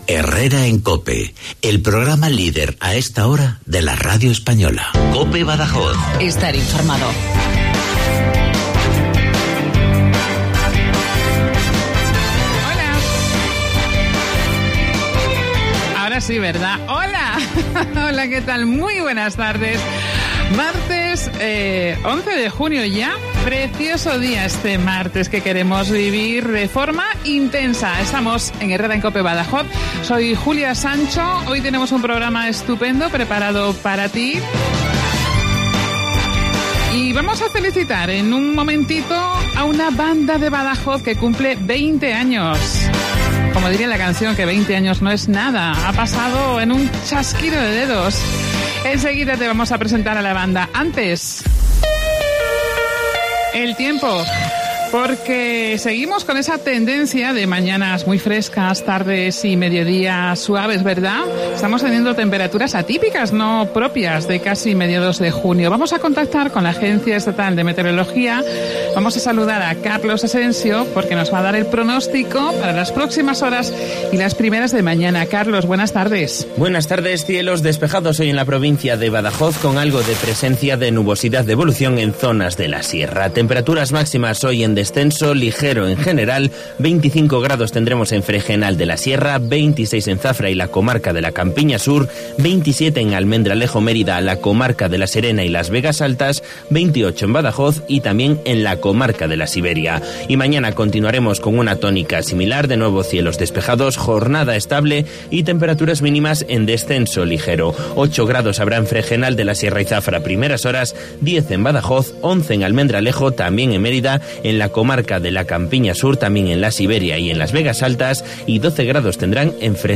Hoy, en Herrera en COPE Badajoz, hemos hablado con uno de los siete miembros de una banda querida y respetada en Badajoz: Vinilo Sánchez Band.